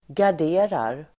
Ladda ner uttalet
Uttal: [gar_d'e:rar]